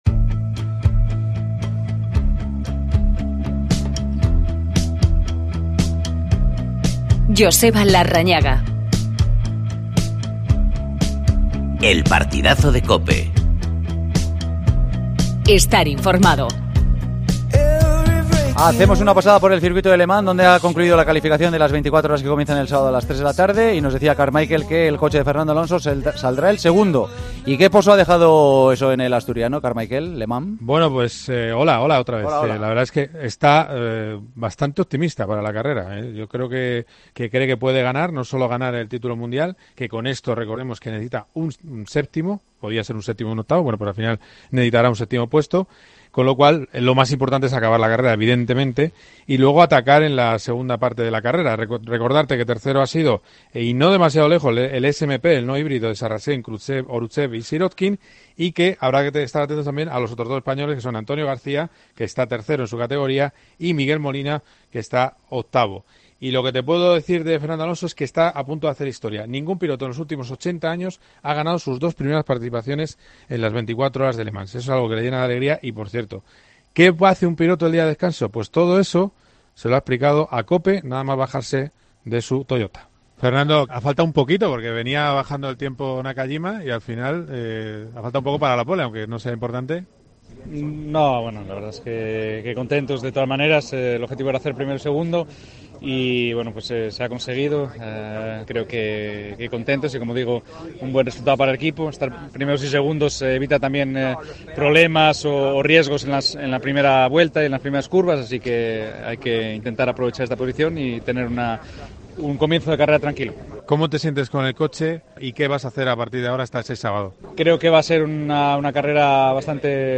Escuchamos a Fernando Alonso.